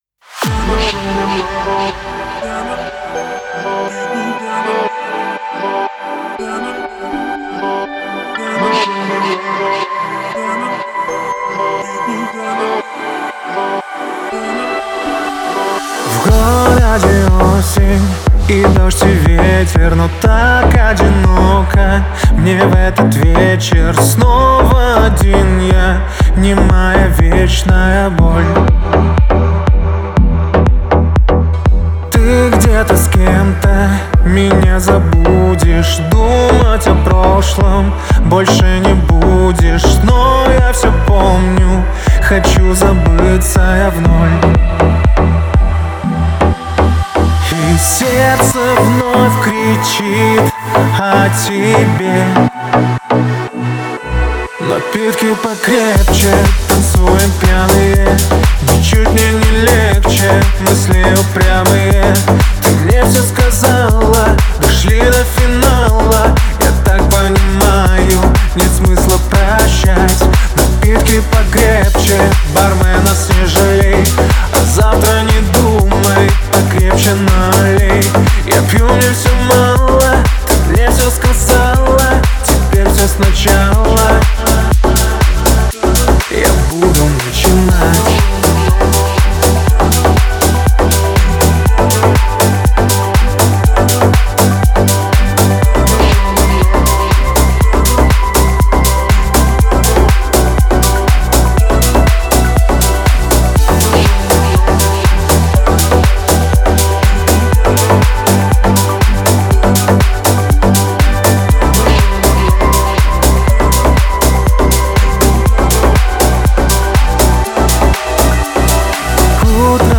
энергичная песня в жанре поп-рэп